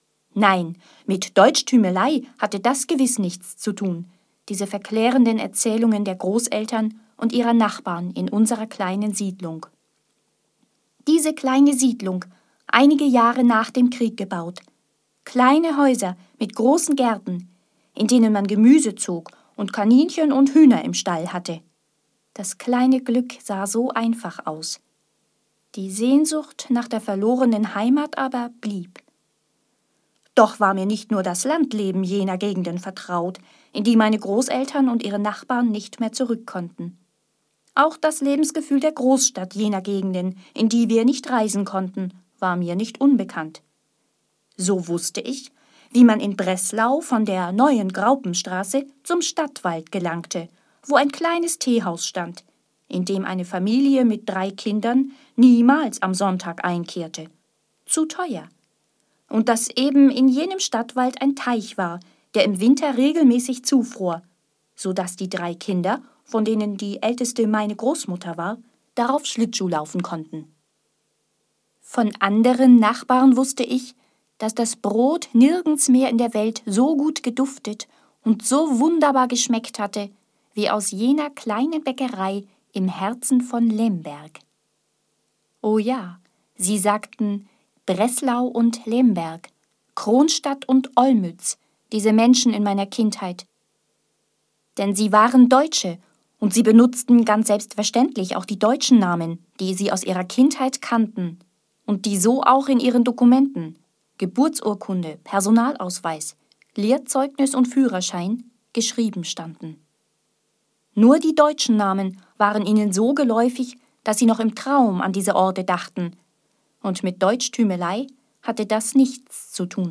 Hier sind einige Beispiele, wie es klingt, wenn ich aus meinen Texten etwas vorlese.
Lesung: Meine Heimat liegt in einer kleinen Siedlung